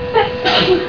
THE SOUND OF SNEEZING
nose_sneeze.wav